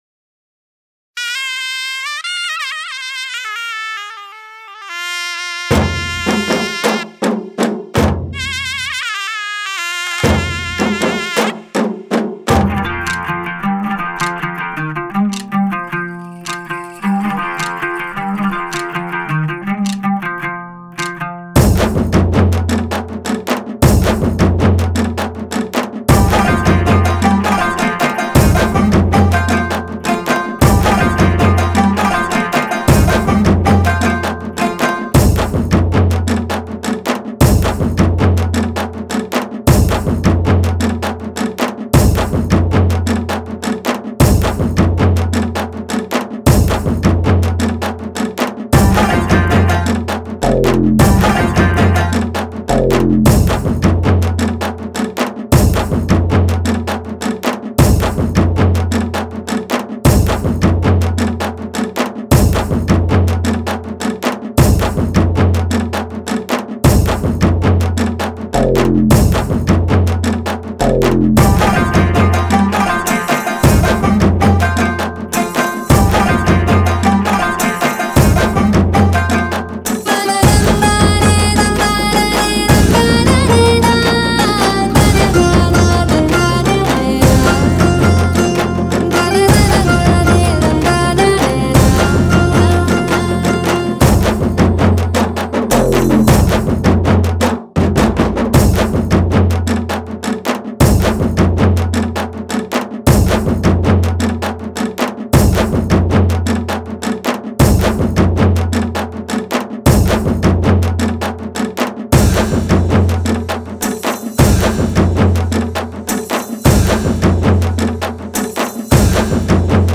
Singer: Karaoke Version